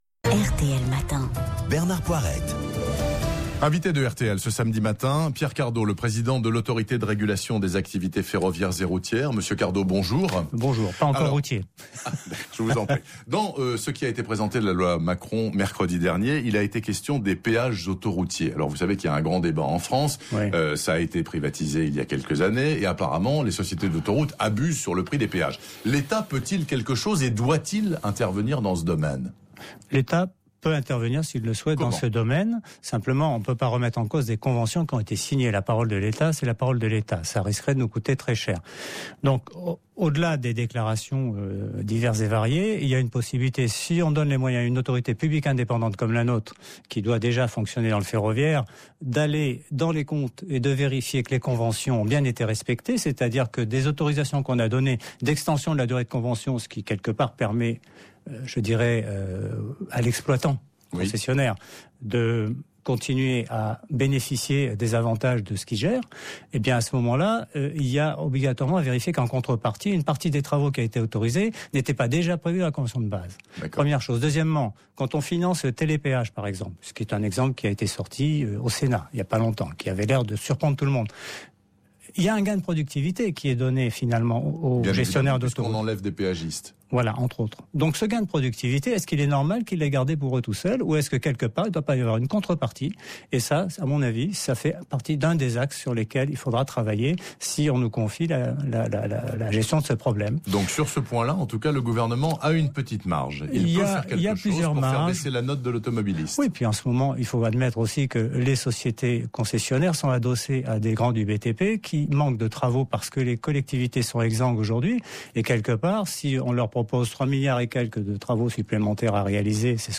Le président de l’Araf était l’invité de RTL samedi 13 décembre, interviewé par Bernard Poirette sur le projet de loi Macron qui envisage de confier au régulateur ferroviaire le contrôle des concessions autoroutières et la régulation des lignes de transport par autocar à l’intérieur d’une même région.